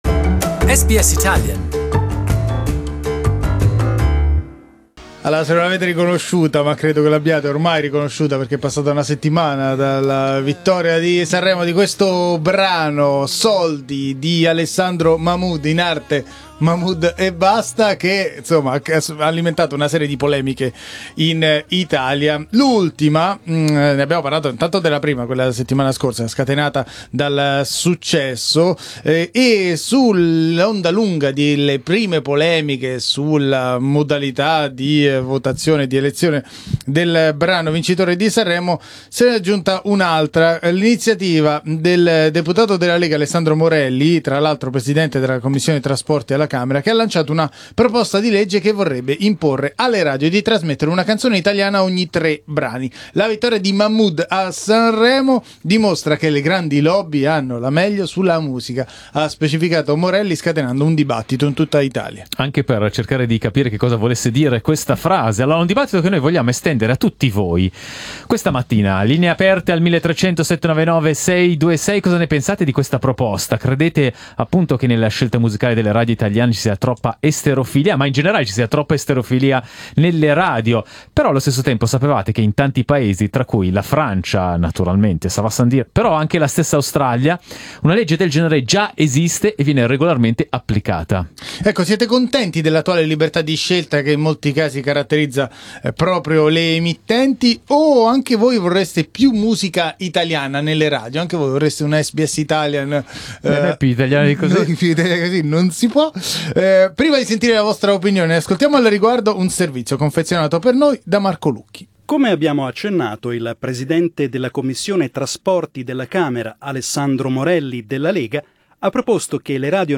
We asked our audience if they agree there should be a legal quota for Italian songs on the radio.